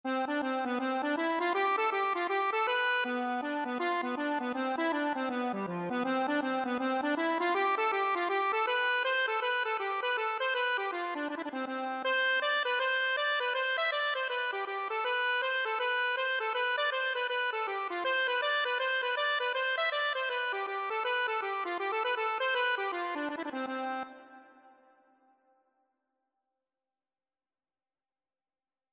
Free Sheet music for Accordion
Traditional Music of unknown author.
C major (Sounding Pitch) (View more C major Music for Accordion )
4/4 (View more 4/4 Music)
Instrument:
Accordion  (View more Easy Accordion Music)
Traditional (View more Traditional Accordion Music)